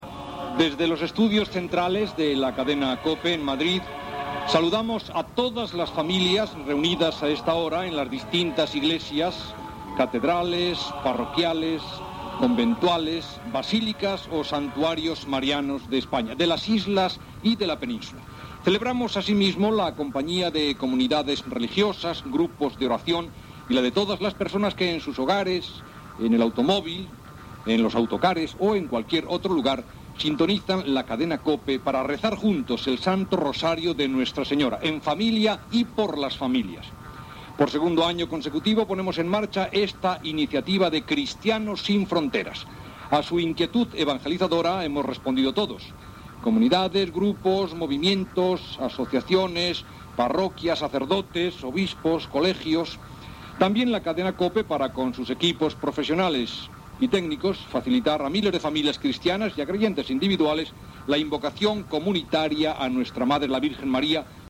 Salutació inicial del rosari conjunt promogut per Cristianos sin fronteras.
Religió